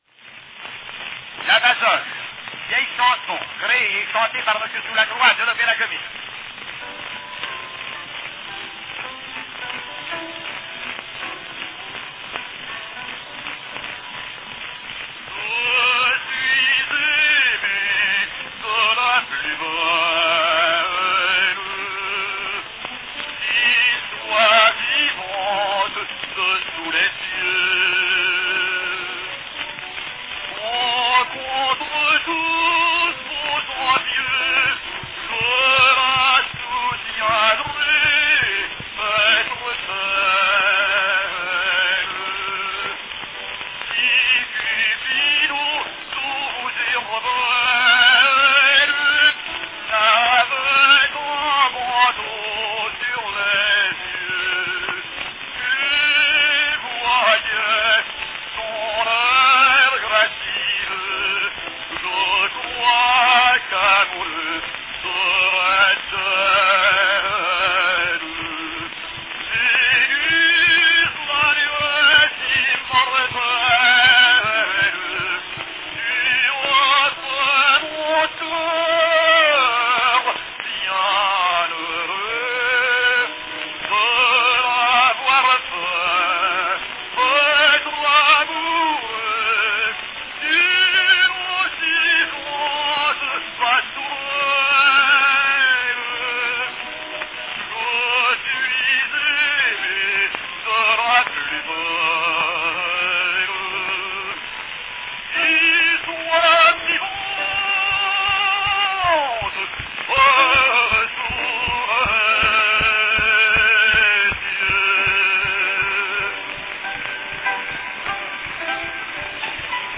From 1900, French baritone Gabriel Soulacroix, in a role he created, sings "Je suis aimé de la plus belle" from the opera La Basoche.
Category Baritone
Performed by Gabriel Soulacroix
Announcement "La Basoche.
La Basoche, a three act comic opera composed by André Messager and based upon a French libretto by Albert Carré, premiered in Paris on May 30, 1890.   In this brown wax cylinder recording, baritone Gabriel Soulacroix sings "Je suis aimé de la plus belle" ("I loved the most beautiful") as Clement Marot in the role he created ten years earlier.